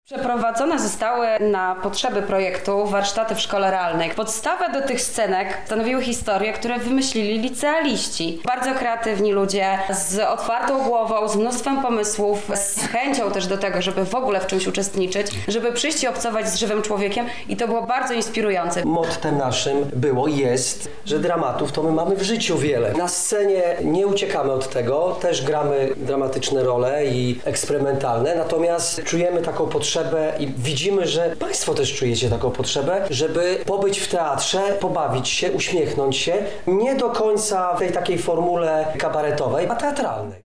Grupa Oł Maj Gad uchyliła rąbka tajemnicy, którą postanowiliśmy poznać na premierze serialu „Branżowcy”.